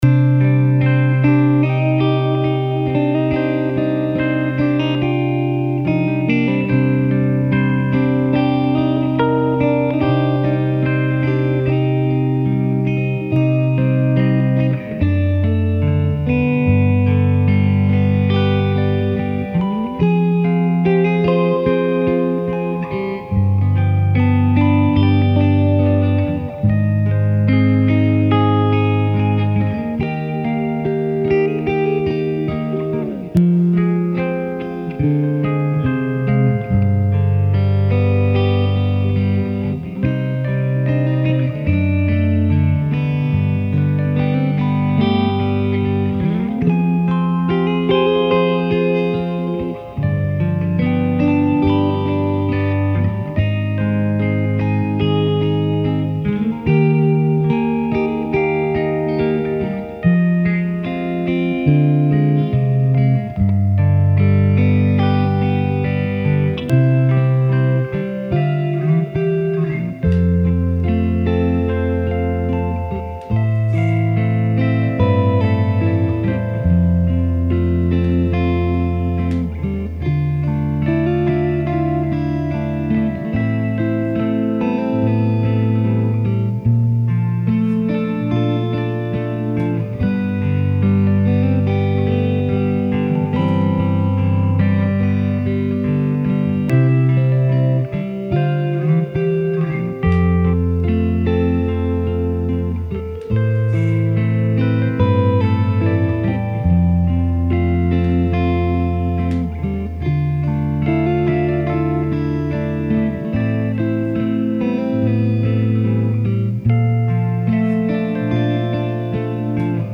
By the way, the background sounds you hear on this version are from my three-year-old playing floor hockey in my garage/studio.
Electric Piano
Prestige Guitars Heritage Elite (sounds acoustic on this song!)
Fender Hot Rod Deluxe (left)